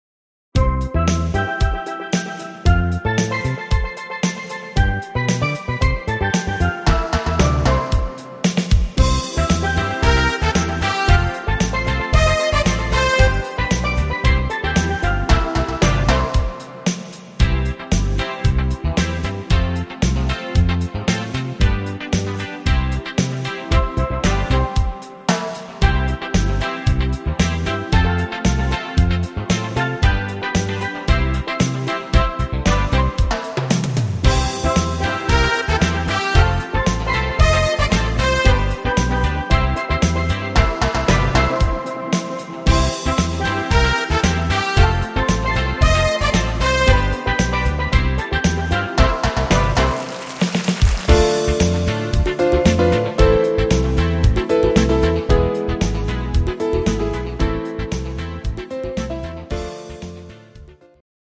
Kurzmedley